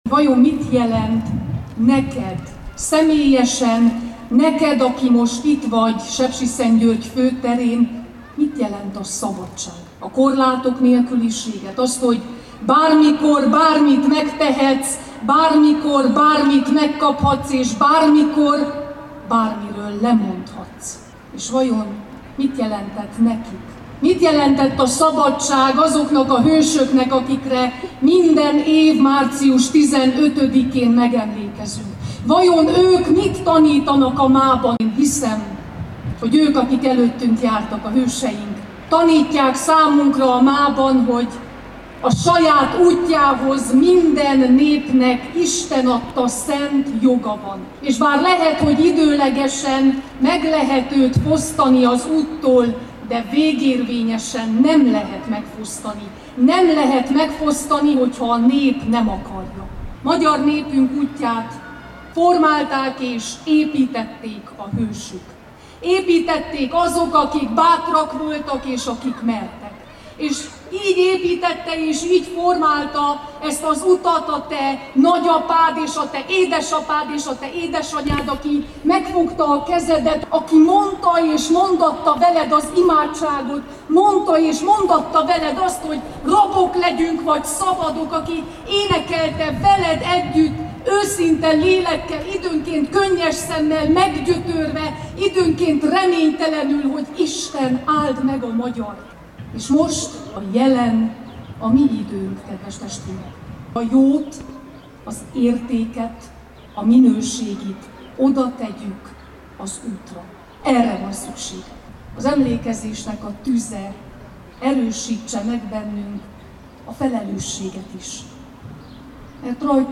Egymásba kell kapaszkodni és egymásból kell erőt meríteni, ezt üzenték március 15-i szónokok Sepsiszentgyörgyön.